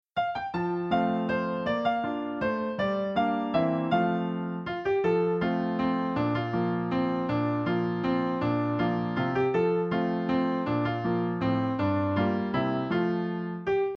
A Classic Children's Song